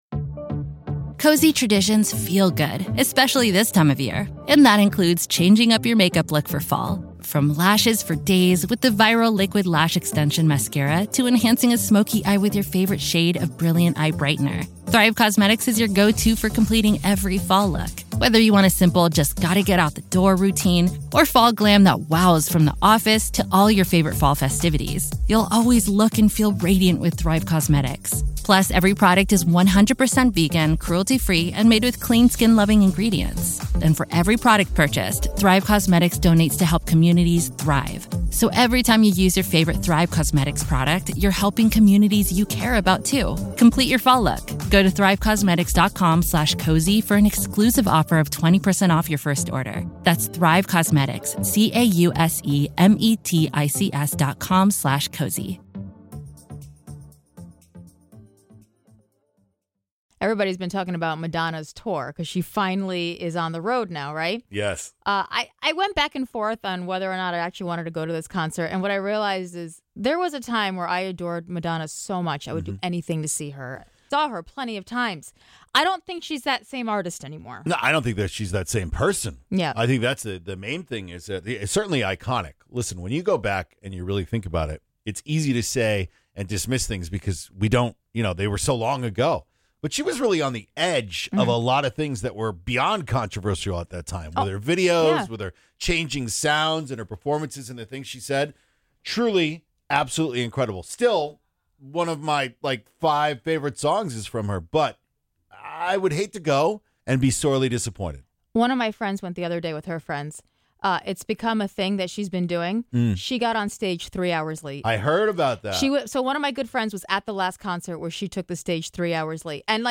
Today’s task is a holiday karaoke contest!